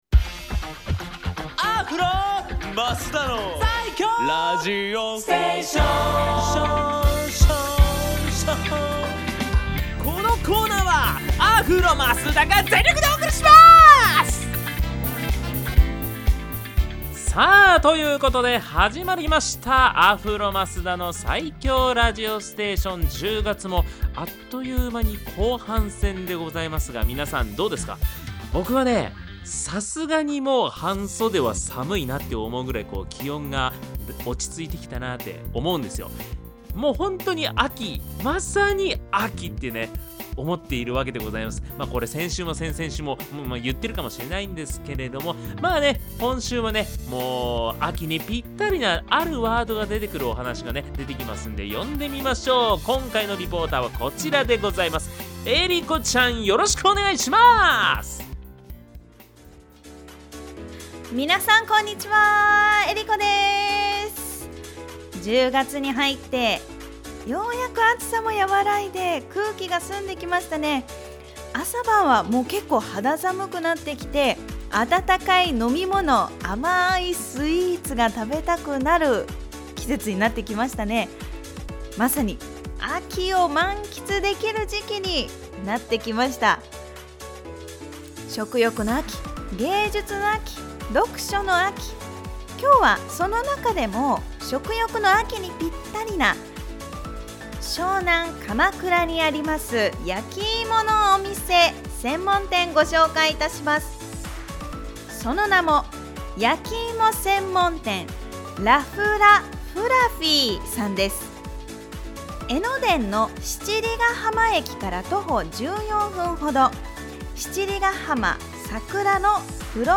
こちらのブログでは、FM83.1Mhzレディオ湘南にて放送されたラジオ番組「湘南MUSICTOWN Z」内の湘南ミュージックシーンを活性化させる新コーナー！
番組では、少し肌寒くなってきた秋の情景にぴったりな曲をお届けしました。